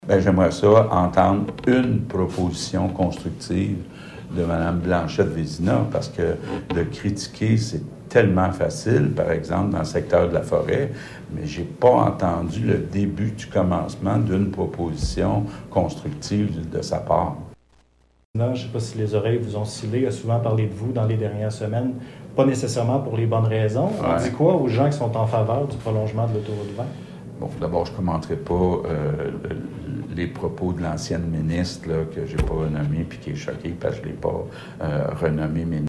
Monsieur Legault était à Rivière-du-Loup cette semaine, pour confirmer un partenariat en énergie éolienne.
Après un peu d’insistance, François Legault a déclaré ce qui suit :